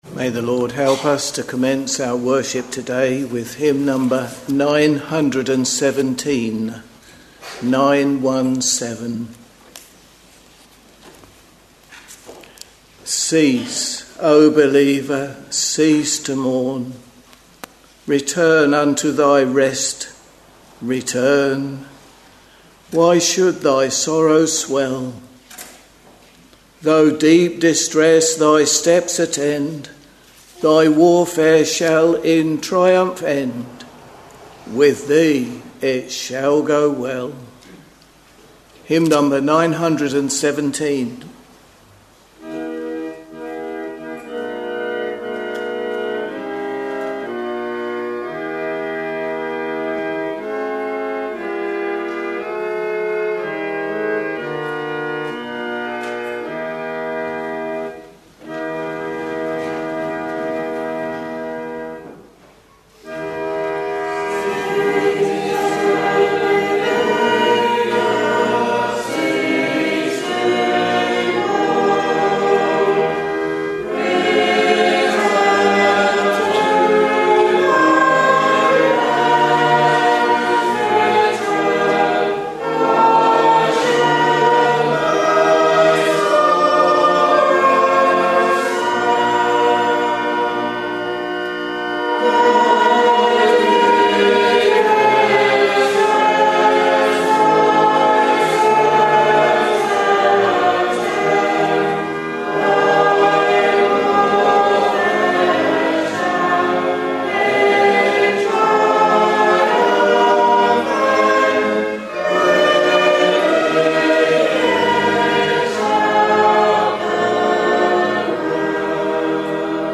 Morning Service Preacher